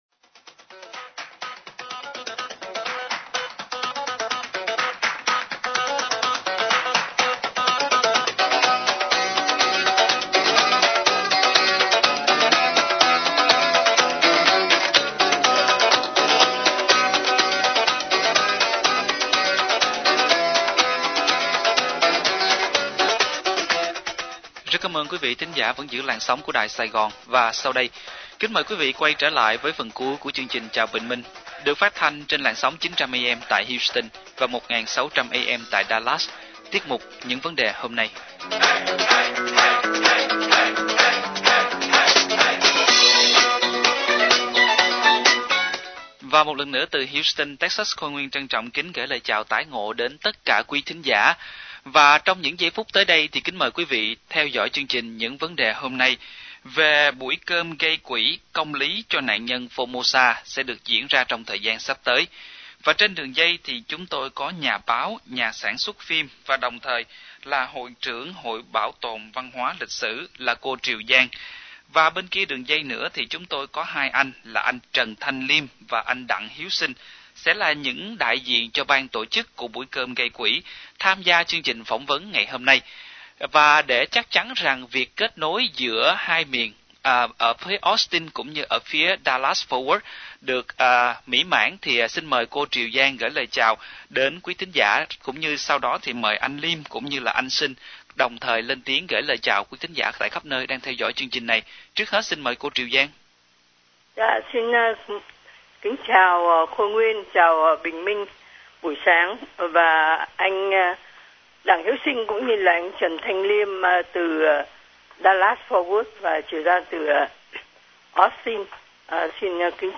Âm thanh buổi phỏng vấn Ban tổ chức Buổi Ca Nhạc gây Quỹ Công Lý Cho Nạn Nhân Formosa tại Dallas 8/4/2018